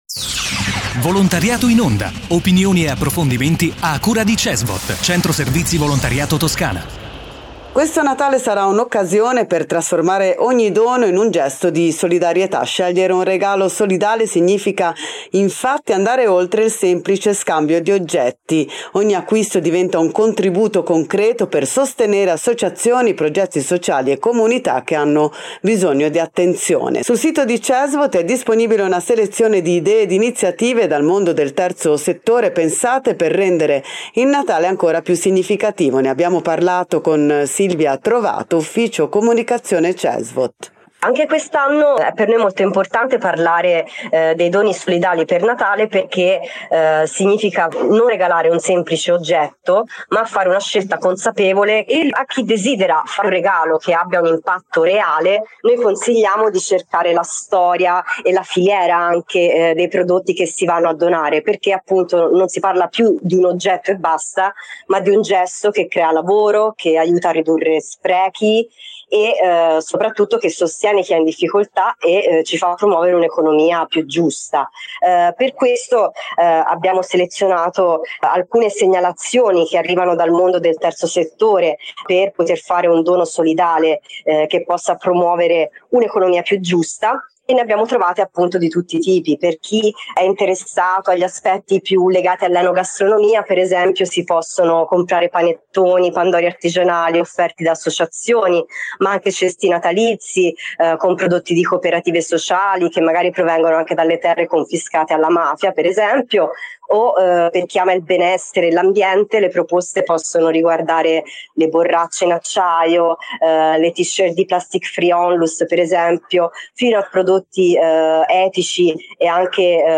Scegliere un regalo solidale significa andare oltre il semplice scambio di oggetti: ogni acquisto diventa un contributo concreto per sostenere associazioni, progetti sociali e comunità che hanno bisogno di attenzione. Intervista